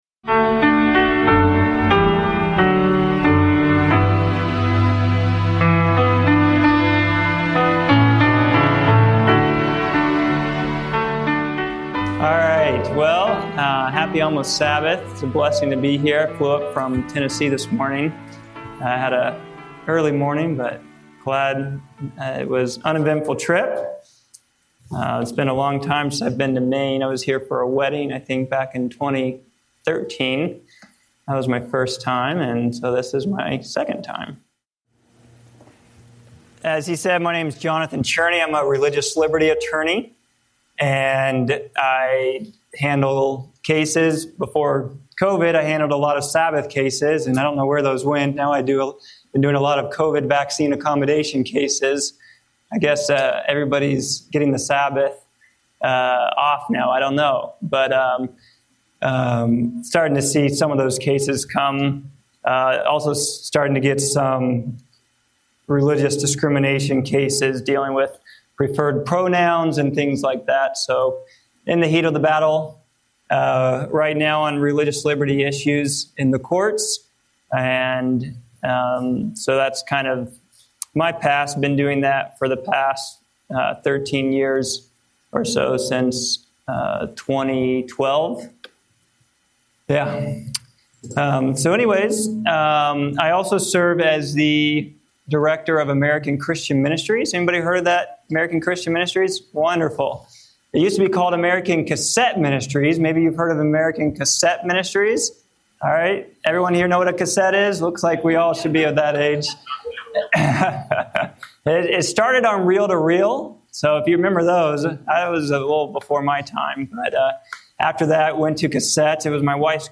In a world of rising confusion and spiritual compromise, this sermon urges believers to stay grounded in authentic faith and the power of the Holy Spirit. Through biblical prophecy, self-examination, and a clear contrast between truth and deception, you're invited to stand firm in Christ in these critical last days.